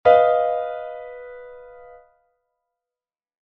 Hartvermindert nennen einige Theoretiker den Dreiklang aus großer Terz und kleiner [verminderter] Quinte bestehend:
Hartvermindert, Beispiel: h-dis-f